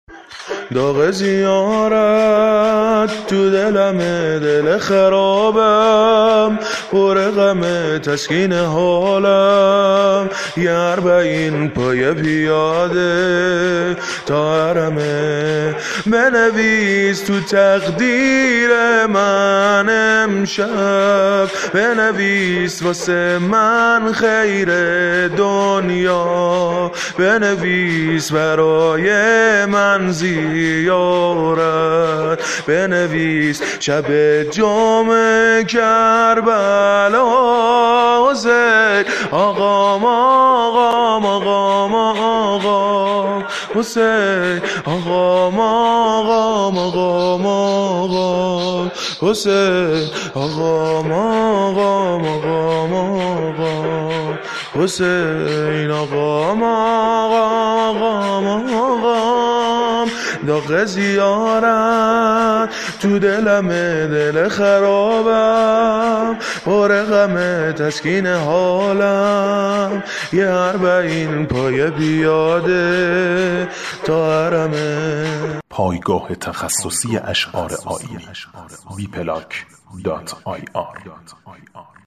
مناجات
شور